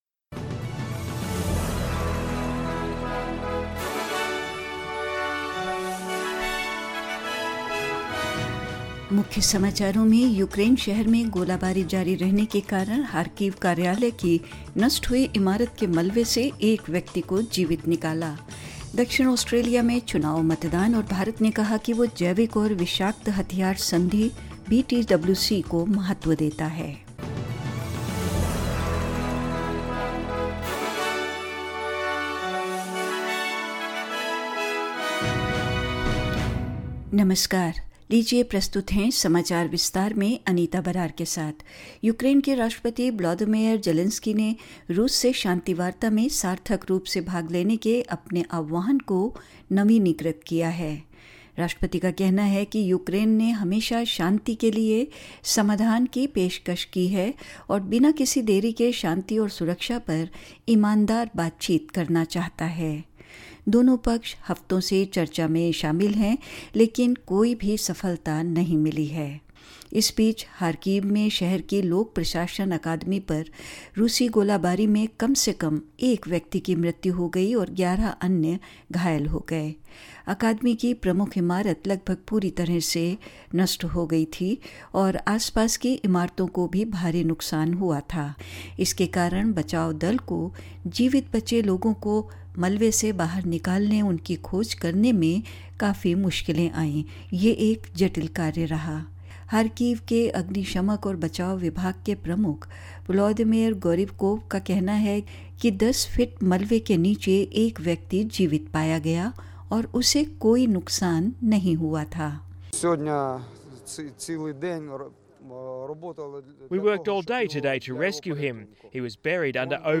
In this latest SBS Hindi bulletin: A man has been pulled alive from the rubble of a destroyed Kharkiv office building as shelling continues in the Ukraine city; Voting underway in elections for both East Timor and South Australia; Australia hoping to book a place in the Women's World Cup semi-finals in their match against India at Auckland's Eden Park and more news.